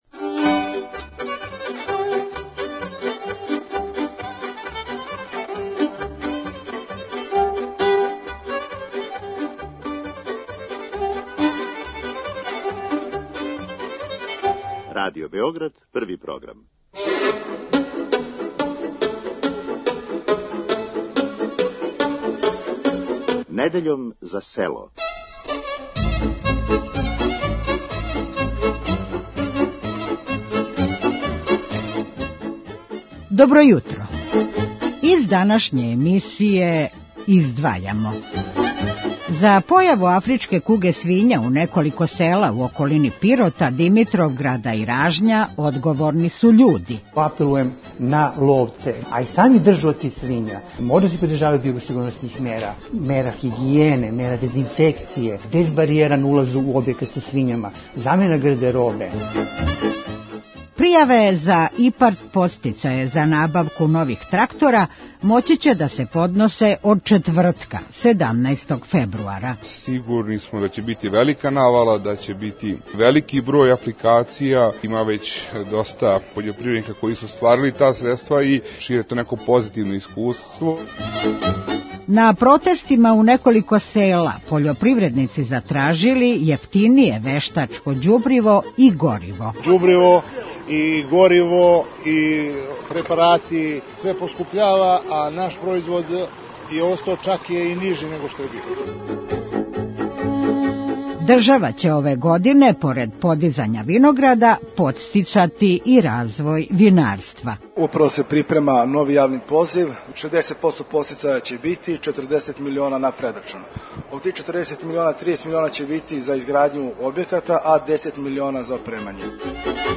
Први програм Радио Београда, недеља, 8,05